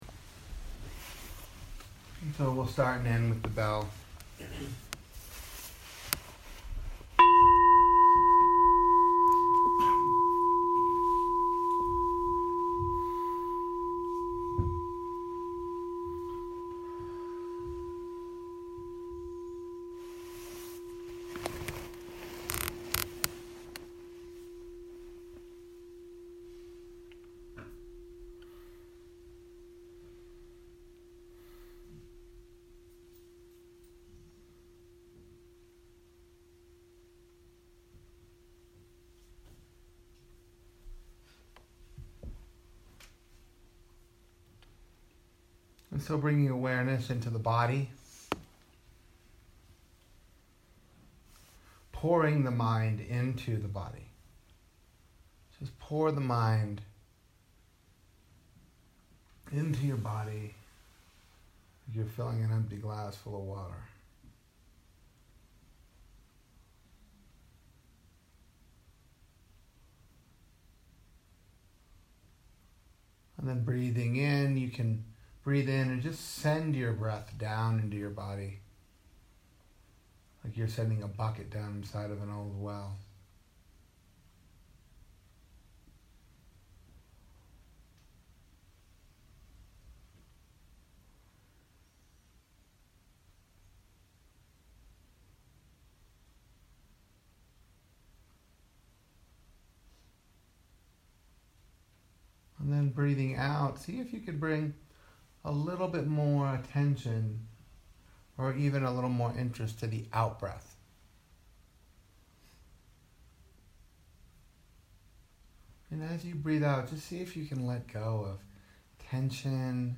This guided practices follows the overview from 2018 Vallecitos retreat. Afternoon session.